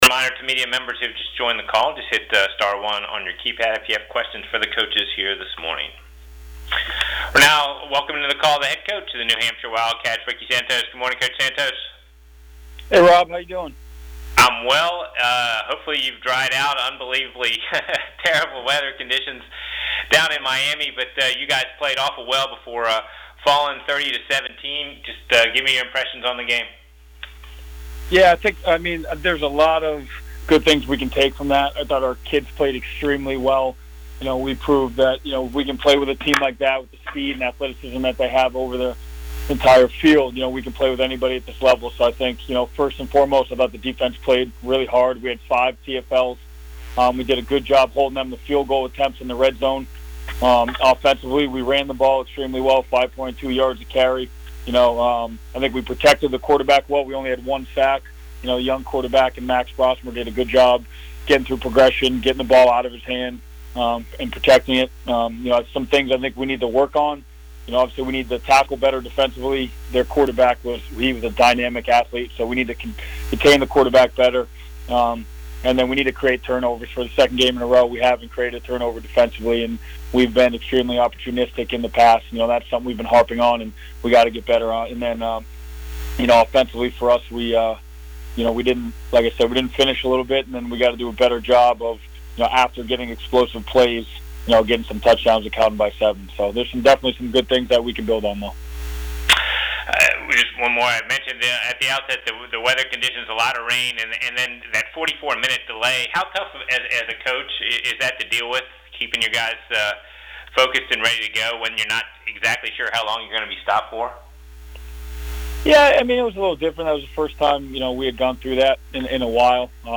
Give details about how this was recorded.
on CAA Weekly Teleconference